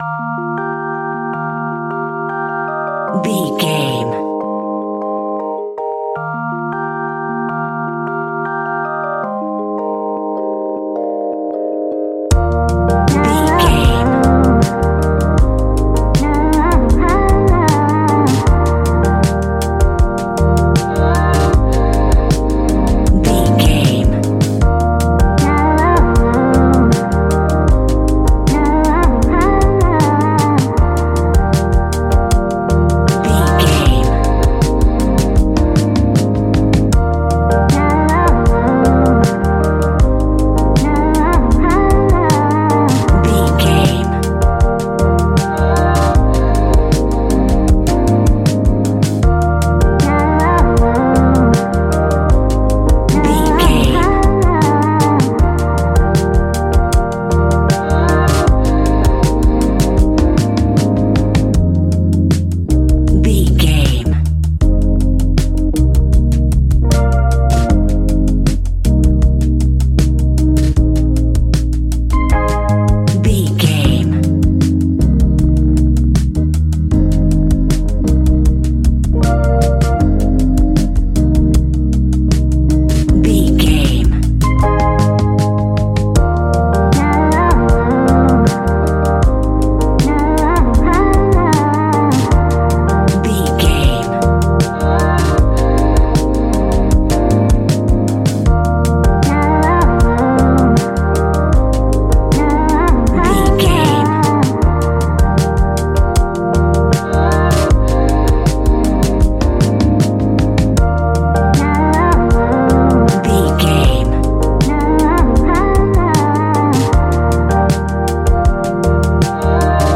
Ionian/Major
D♯
chilled
laid back
Lounge
sparse
new age
chilled electronica
ambient
atmospheric